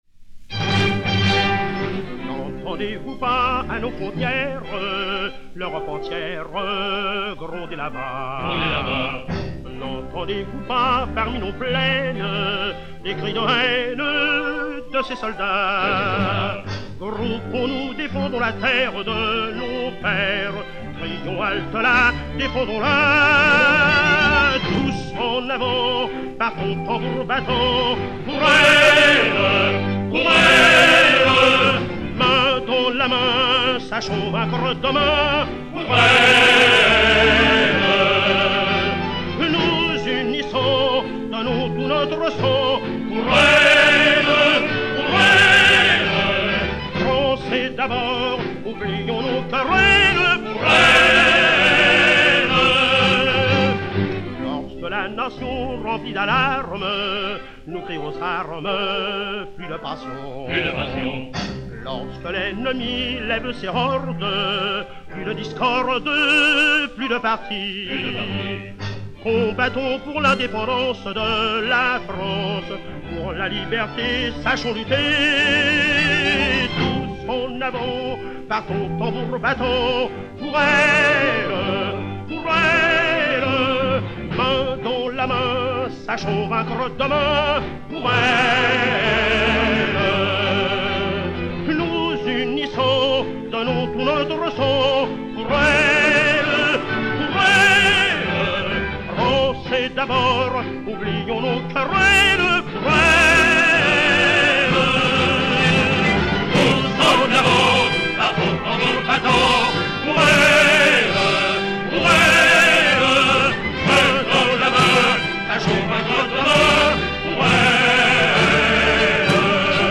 Marche